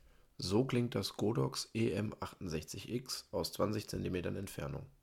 Klang aus 20 Zentimetern Entfernung
Der Klang des Godox EM68X ist gut, wenn man aus mindestens 20 Zentimetern hineinspricht.
Den Rauschunterdrückungsmodus hätte ich gern ausprobiert, denn im Hintergrund ist beim Godox EM68X immer ein latentes Grundrauschen zu vernehmen. Angenehm ist, dass der Popschutz direkt im Mikrofon verbaut ist und somit scharfe Laute wie „k, t oder p“ besser abgefedert werden.